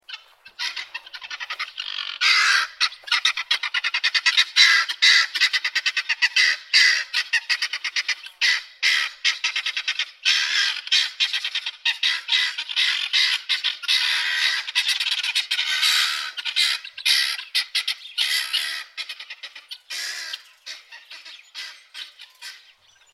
Guinea Fowl Sounds
Animal Sounds / Chicken Sounds / Sound Effects
Guinea-fowl-sounds.mp3